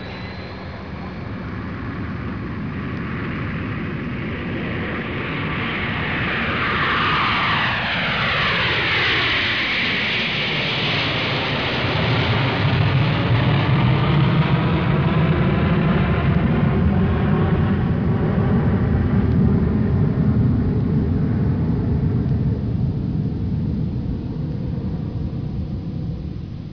دانلود آهنگ طیاره 3 از افکت صوتی حمل و نقل
جلوه های صوتی
دانلود صدای طیاره 3 از ساعد نیوز با لینک مستقیم و کیفیت بالا